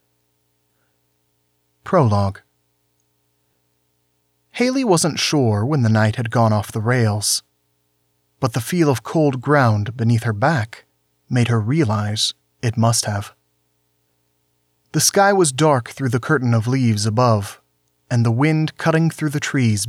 Special Interest Groups Audiobook Production
I have a raw file and an edited file for comparison, and I’d love any feedback I can get before I go on to do the full recording and mastering.
You have wallpower hum drifting through the performance.
Your raw recording is clear, but really low volume. If your voice was louder, the background buzzzzzz wouldn’t be as objectionable.